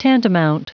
Prononciation du mot tantamount en anglais (fichier audio)
Prononciation du mot : tantamount